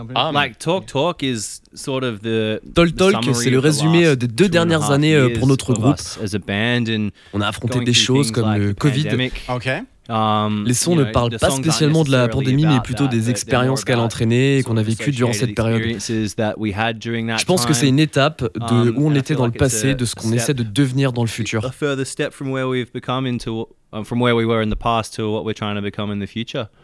Ils étaient en live depuis nos studios